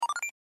Звуки съемки видео
Звук старта записи